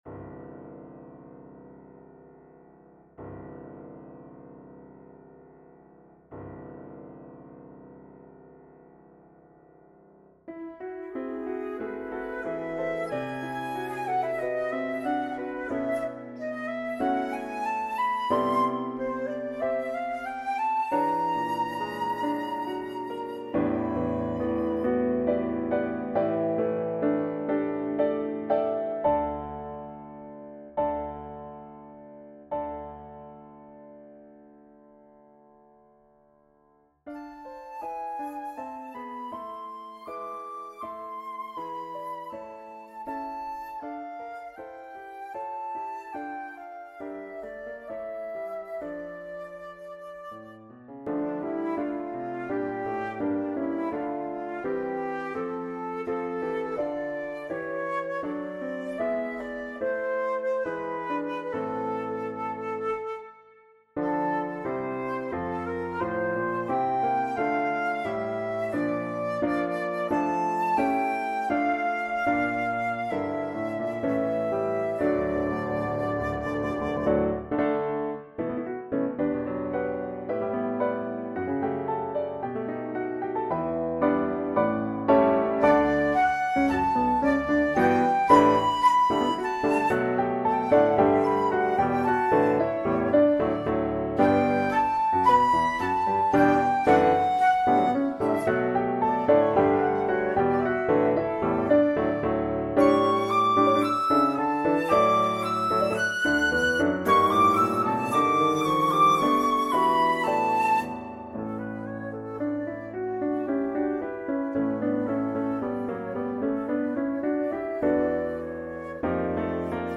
arranged for Flute and Piano.